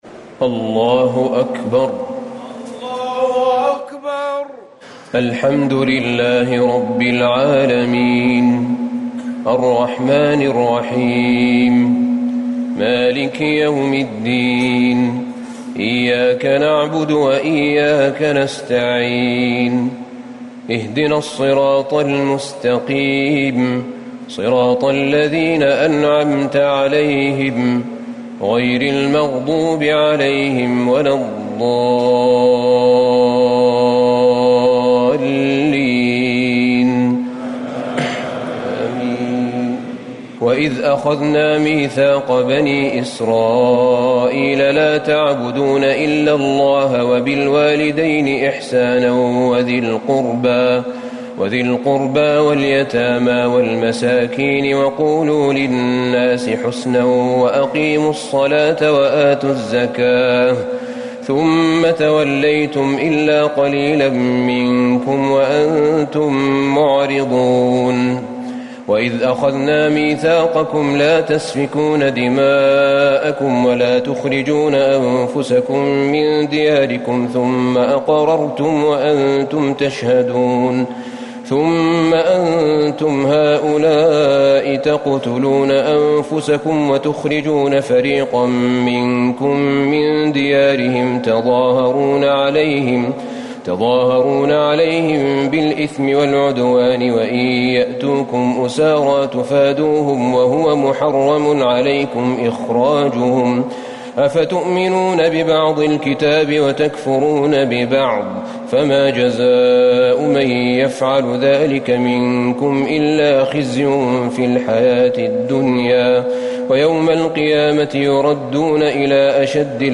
ليلة ١ رمضان ١٤٤٠هـ من سورة البقرة ٨٣-١٥٧ > تراويح الحرم النبوي عام 1440 🕌 > التراويح - تلاوات الحرمين